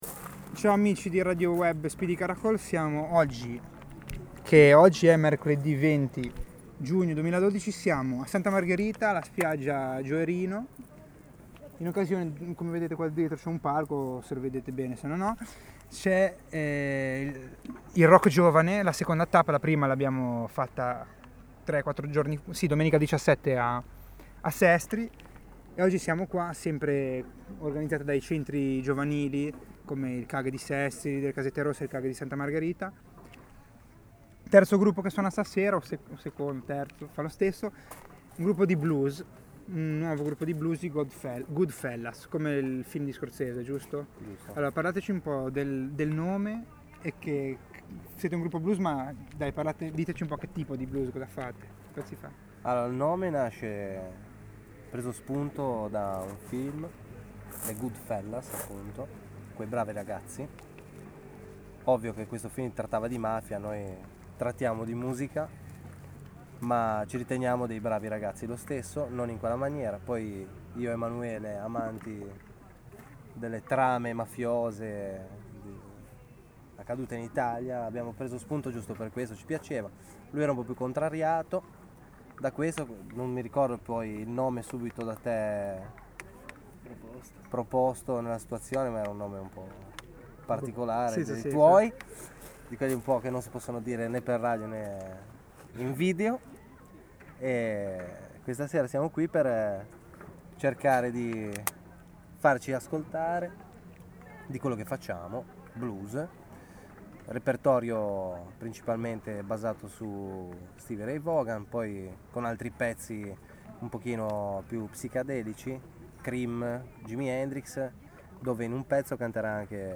Intervista a GoodFellas